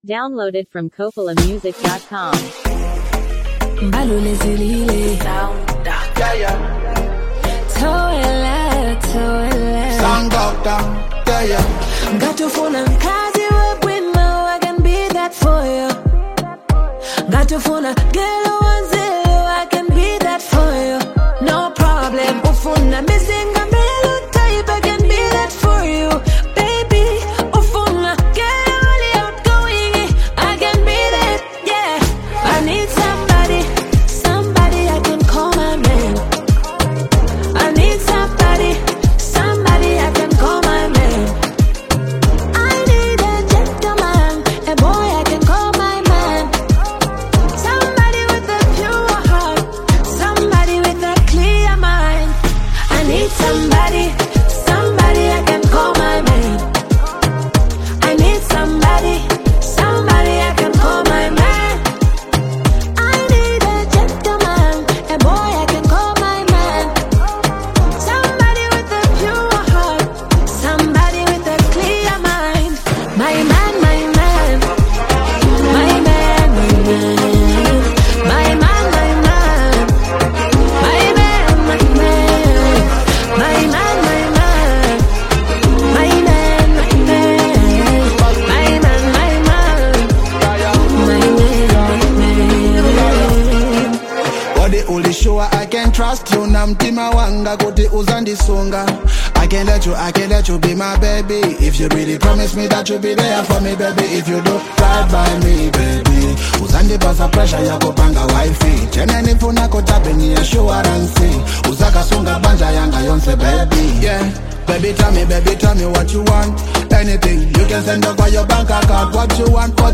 Sounds like a romantic duet 🎵!